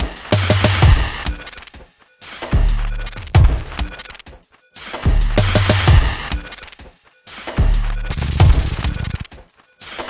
描述：一个带有轻微偏色的节奏的环境节拍。
Tag: 95 bpm Weird Loops Drum Loops 1.70 MB wav Key : Unknown